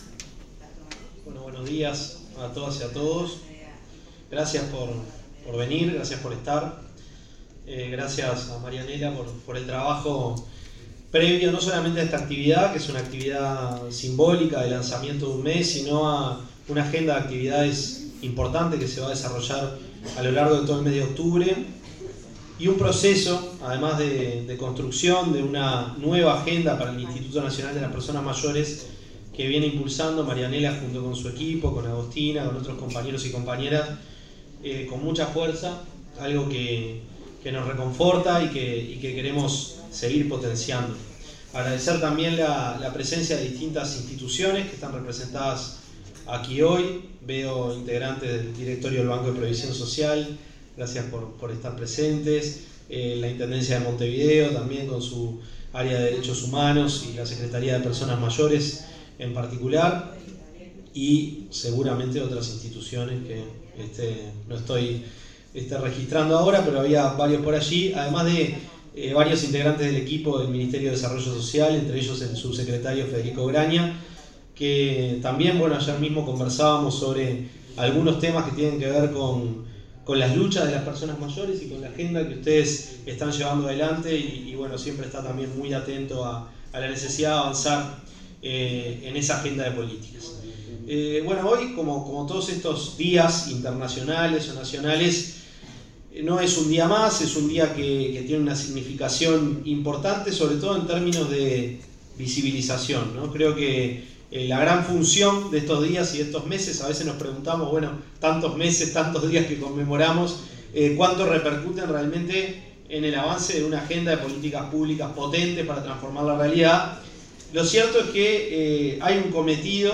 Palabras del ministro de Desarrollo Social, Gonzalo Civila
El ministro de Desarrollo Social, Gonzalo Civila, se expresó en el lanzamiento del Mes de las Personas Mayores, que se realiza bajo la consigna “La